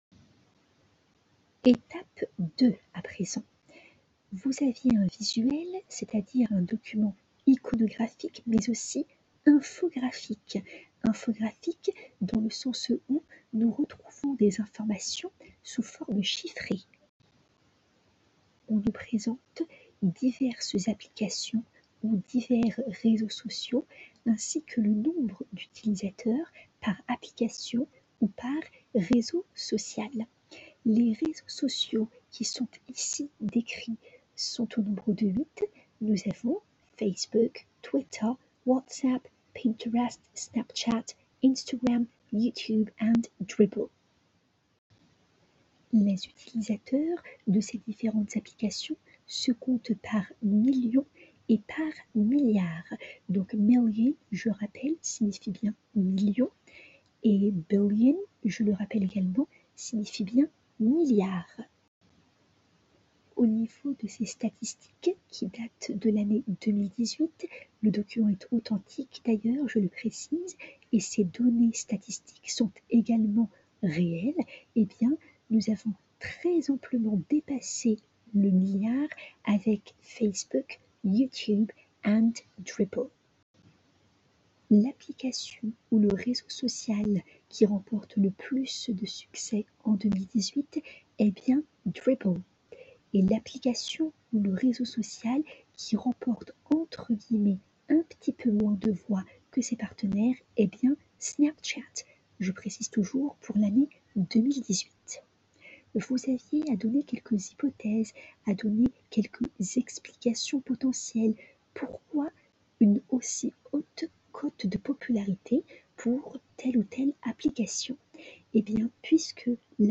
Je vous souhaite une très bonne écoute des pistes audio ci-dessous mettant en avant les explications orales du professeur relativement à la leçon à laquelle vous aviez à réfléchir, en classe inversée, pour le jeudi 02 avril 2020.
Audio 2 du professeur, d'une durée de 03:28: